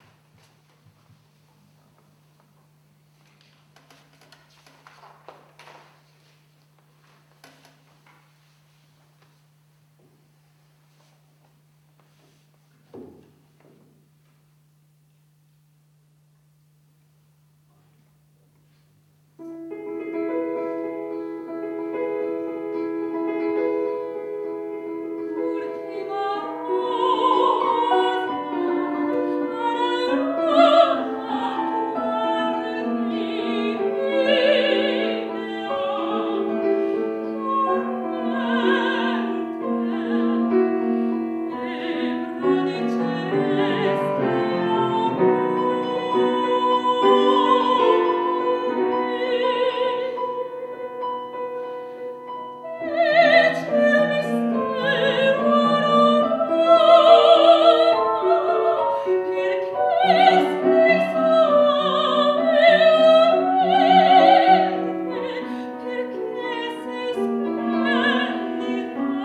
III OTTOBRE MUSICALE A PALAZZO VALPERGA
soprano
pianoforte - Riccardo Zandonai L'ultima rosa